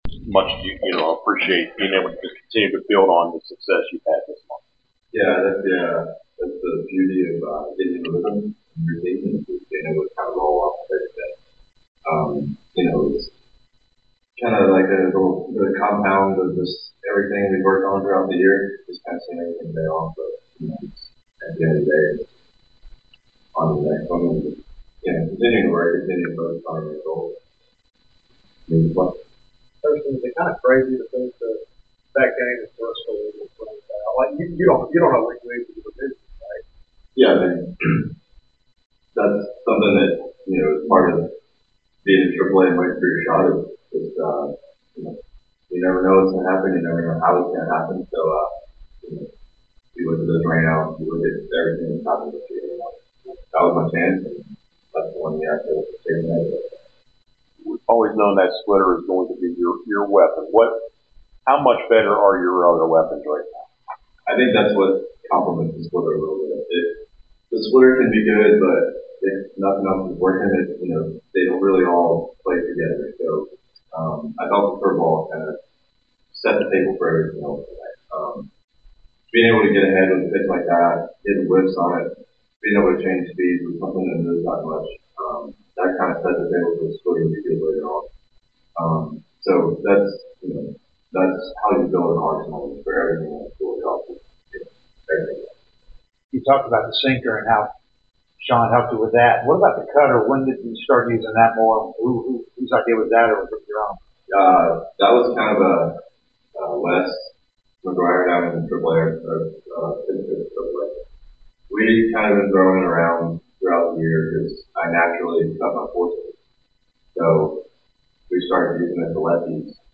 08-20-25 Atlanta Braves Pitcher Hurston Waldrep Postgame Interview
Atlanta Braves Pitcher Hurston Waldrep Postgame Interview after defeating the Chicago White Sox at Truist Park.